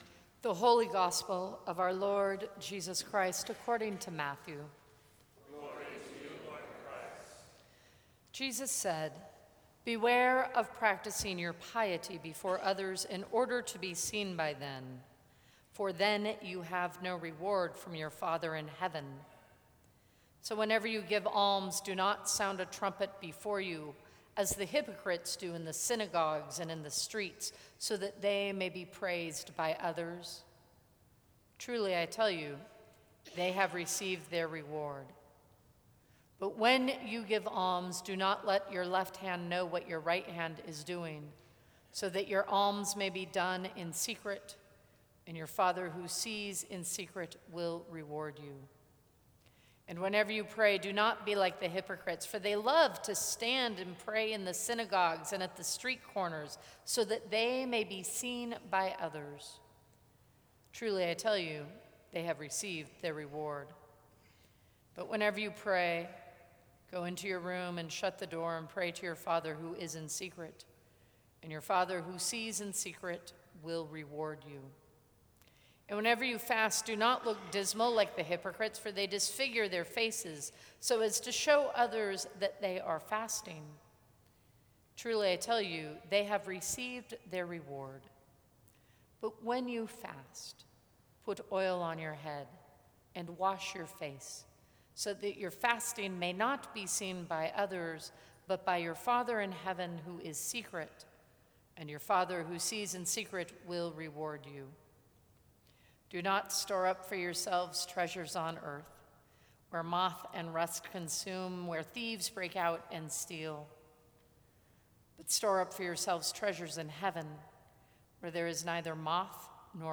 Sermons from St. Cross Episcopal Church Ash Wednesday Feb 22 2016 | 00:12:16 1x 00:00 / 00:12:16 Subscribe Share Apple Podcasts Spotify Overcast RSS Feed Share Link Embed